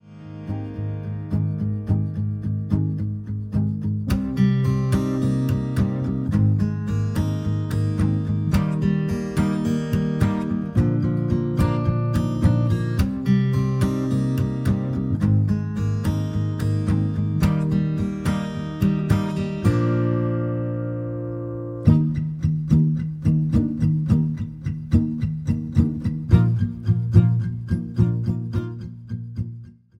Eb
MPEG 1 Layer 3 (Stereo)
Backing track Karaoke
Pop, 2010s